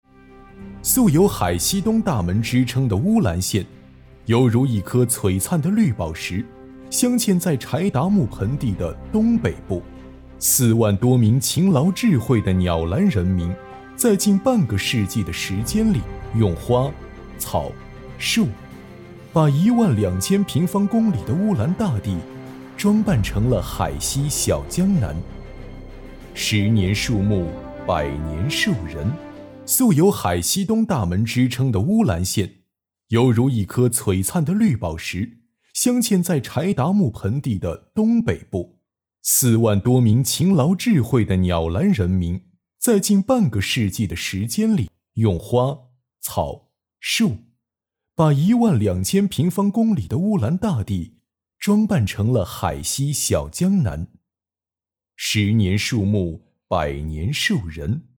5 男国472_专题_政府_素有海西东大门之称的乌兰县 男国472
男国472_专题_政府_素有海西东大门之称的乌兰县.mp3